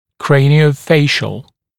[ˌkreɪnɪə(u)ˈfeɪʃl][ˌкрэйнио(у)ˈфэйшл]черепно-лицевой